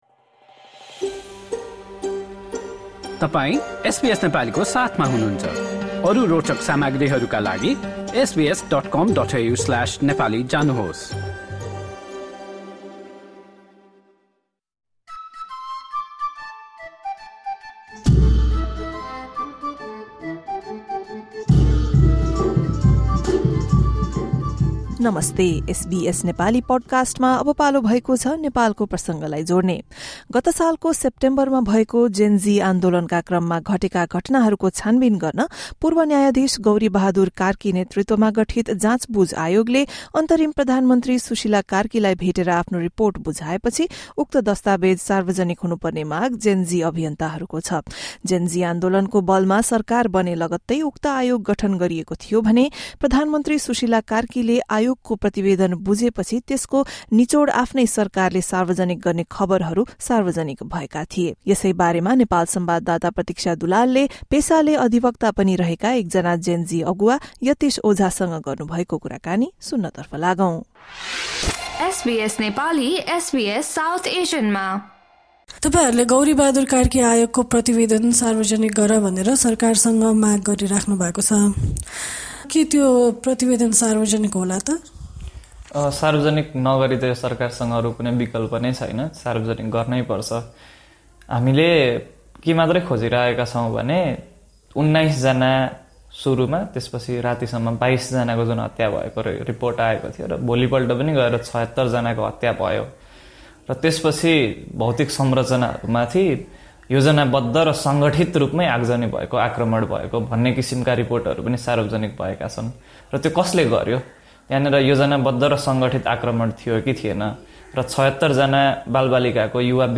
spoke with lawyer and Gen Z activist